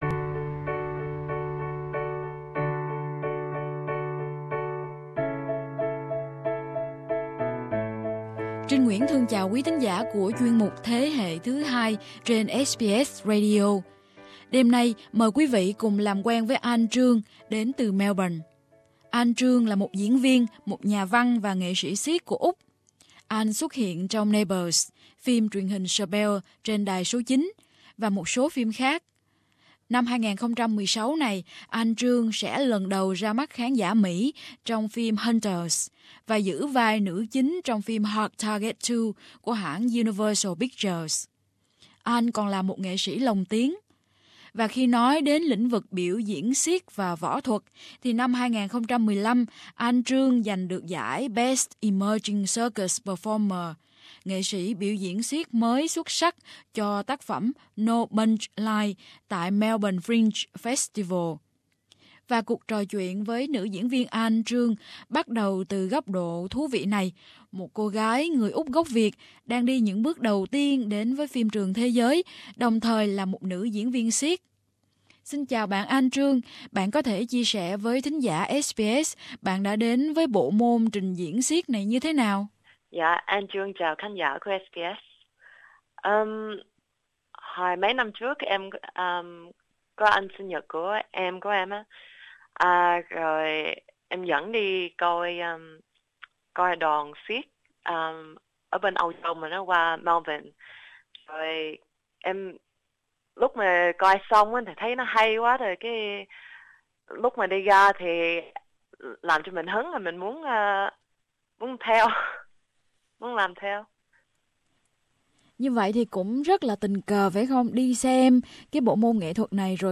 Trò chuyện